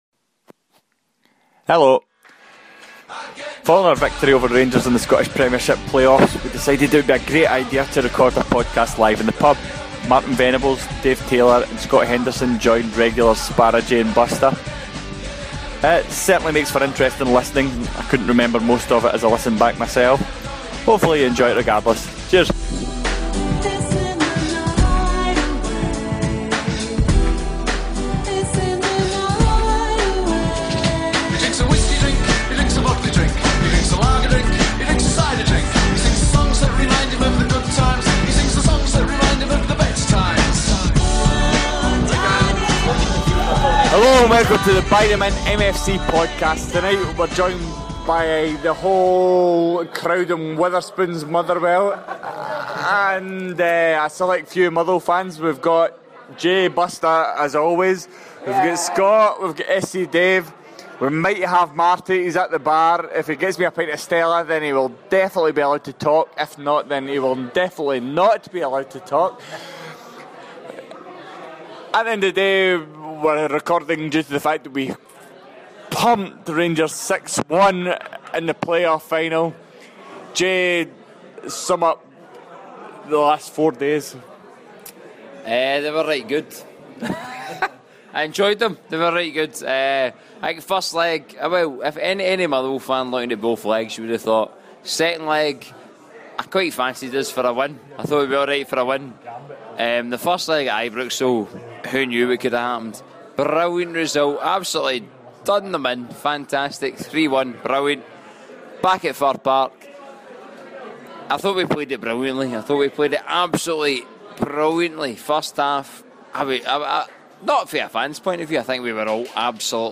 As the title may suggest, this was recorded in the pub in Motherwell following the glorious 2nd leg playoff victory at Fir Park. And also after a large amount of alcohol over the course of the day. Needless to say the result is a load of drunken mince – do not expect anything remotely professional, intelligent, or clean (we’ve even bleeping some stuff out)!